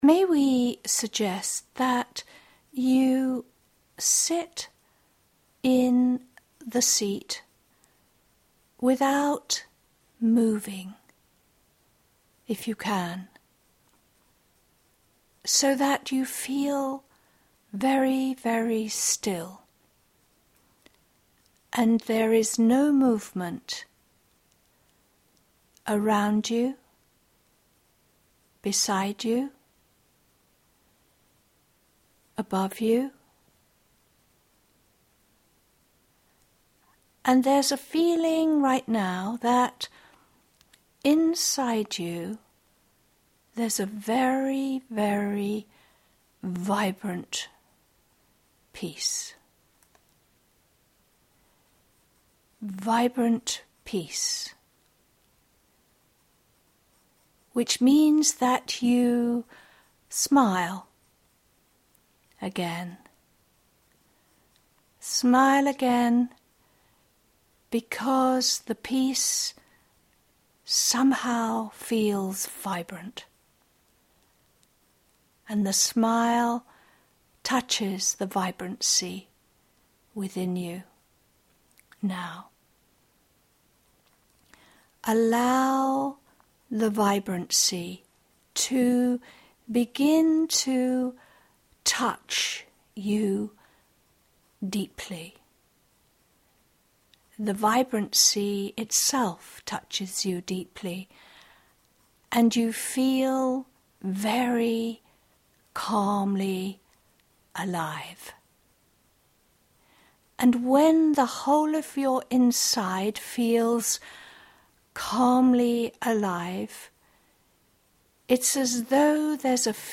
October-Meditation.mp3